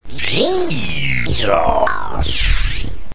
They have developed the first known True Christian Operating System: JesOS.� The system is being released to the Christian public, although the log-in sound effect still needs some work.�